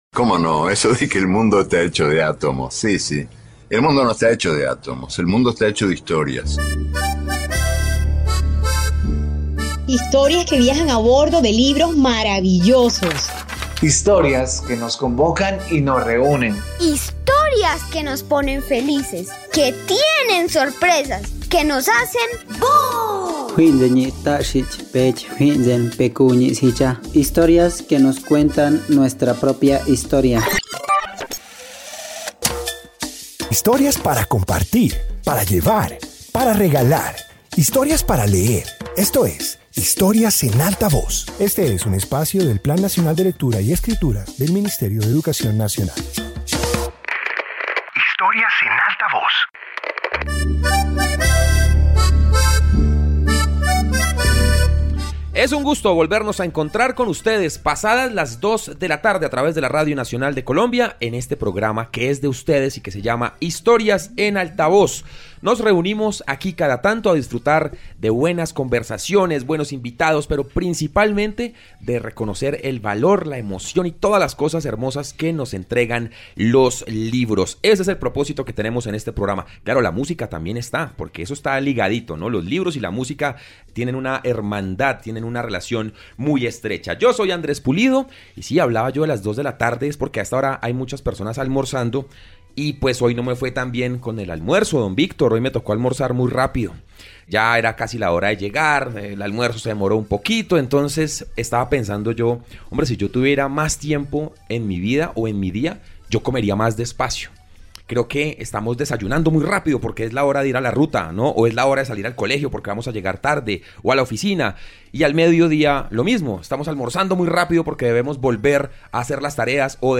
Introducción Este episodio de radio explora distintas formas de percibir el tiempo. Presenta relatos que abordan su paso, su influencia en la vida cotidiana y las maneras en que lo comprendemos.